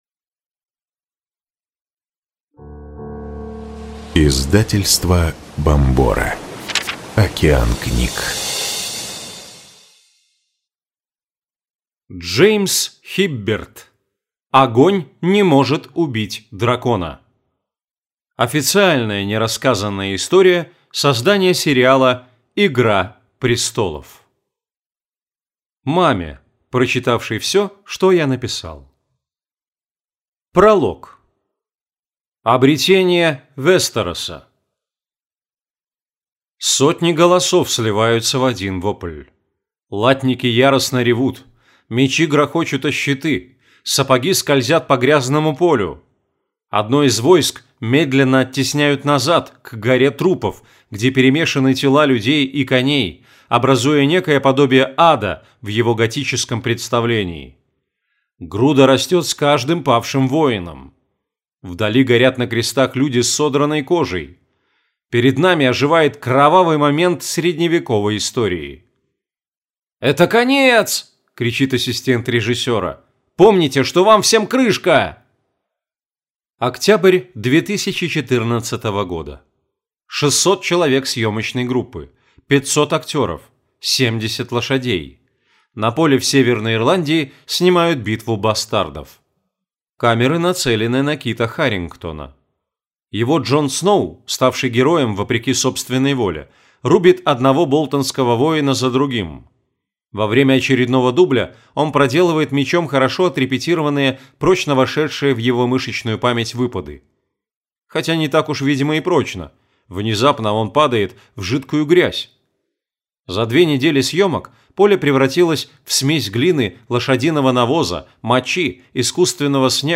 Аудиокнига Огонь не может убить дракона. Официальная нерассказанная история создания сериала «Игра престолов» | Библиотека аудиокниг